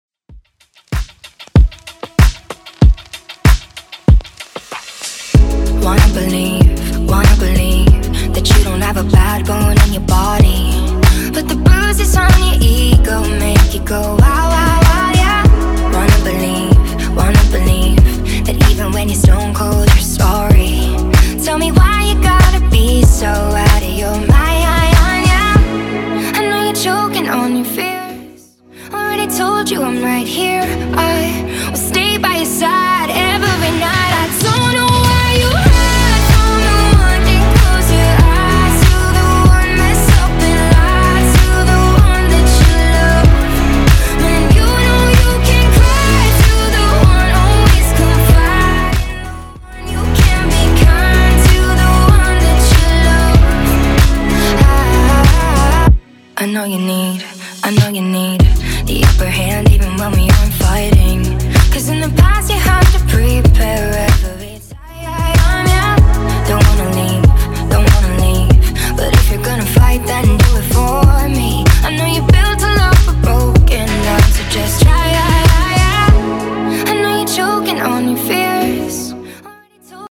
Genre: 70's
BPM: 148